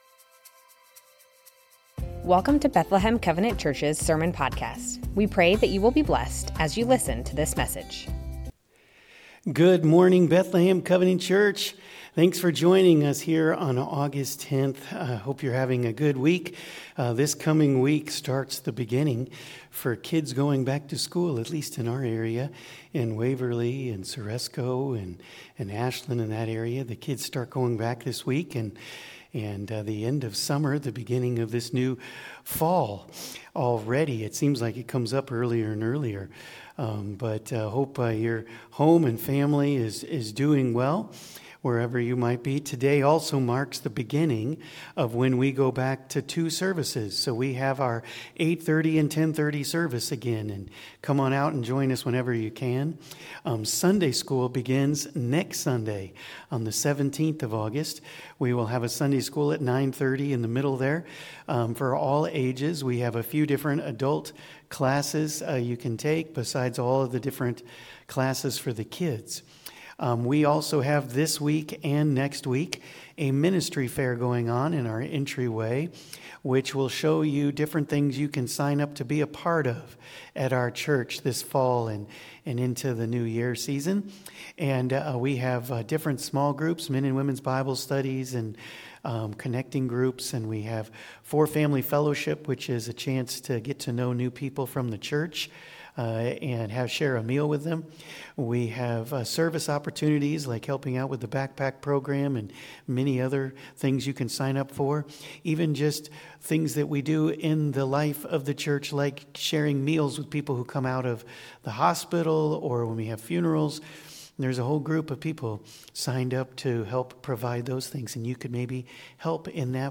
Bethlehem Covenant Church Sermons Names of God - Elohim Aug 10 2025 | 00:35:01 1x 00:00 / 00:35:01 Subscribe Share Spotify RSS Feed Share Link Embed